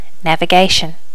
Additional sounds, some clean up but still need to do click removal on the majority.
navigation.wav